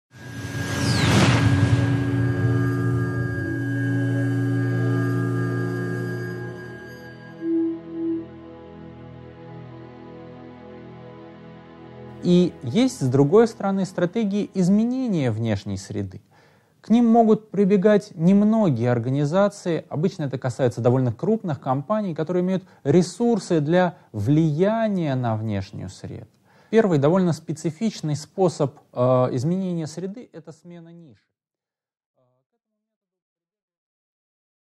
Аудиокнига 8.7. Итоги: реакция организации на изменения внешней среды, продолжение | Библиотека аудиокниг
Прослушать и бесплатно скачать фрагмент аудиокниги